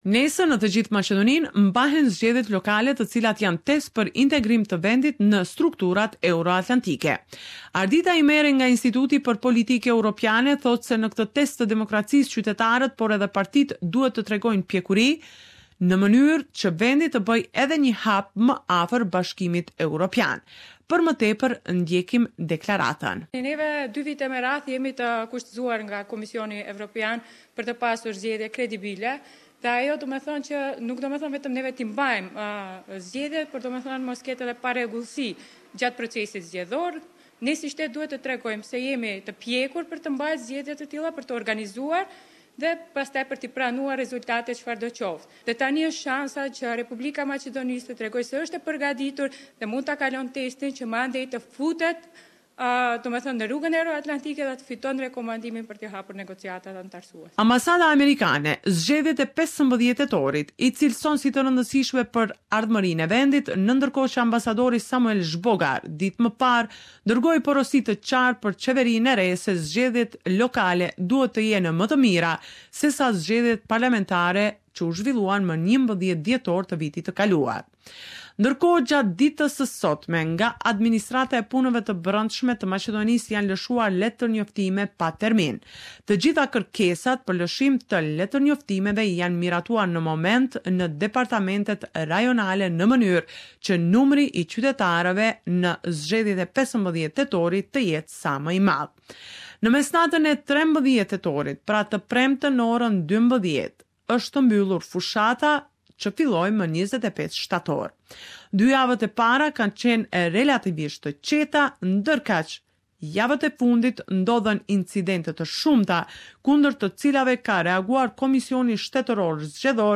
This is a report summarising the latest developments in news and current affairs in FYROM.